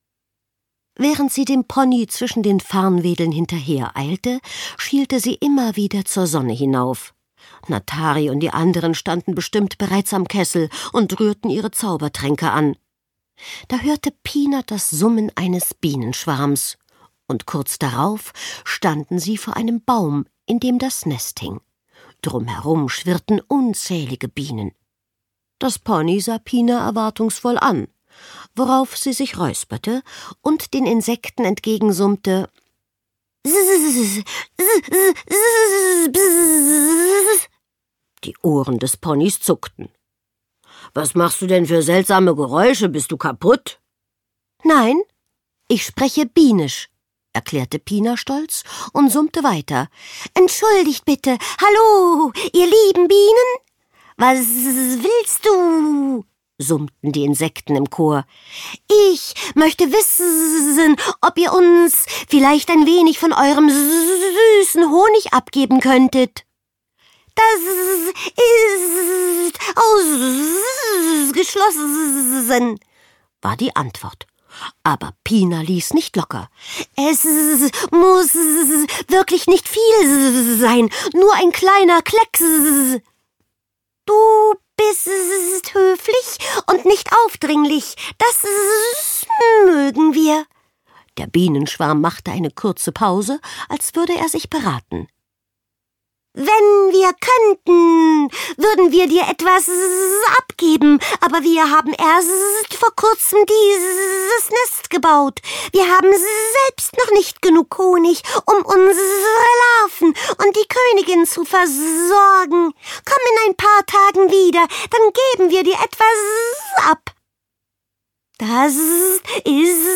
Pina Ponyhexe – Eine magische Freundschaft Zauberhafte Ponygeschichte mit einer frechen Hexe│Kinderhörbuch ab 6 Jahren Marlene Jablonski